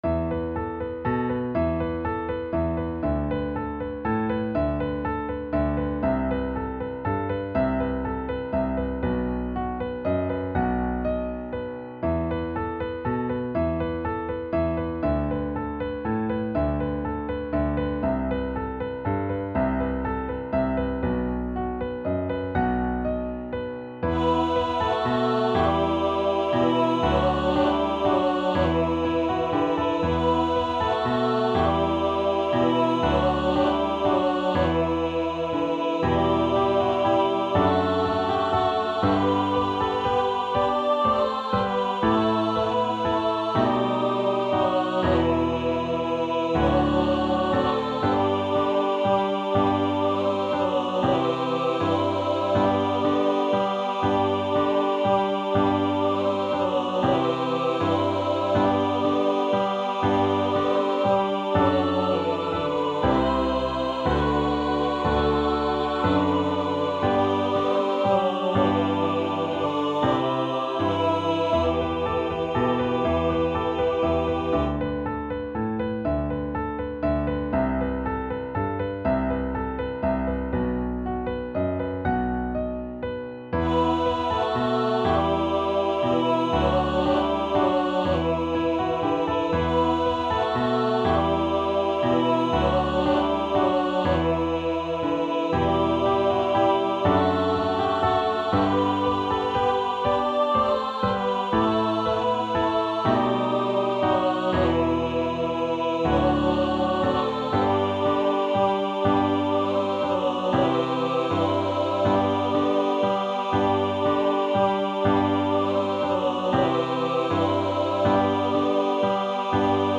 • Music Type: Choral
• Voicing: Congregation, Descant, SAB
• Accompaniment: Piano
playful rhythmic variations